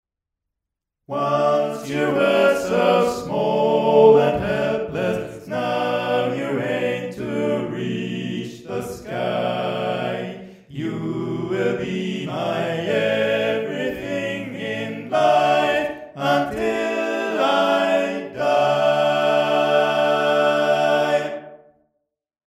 Key written in: D Major
Type: Barbershop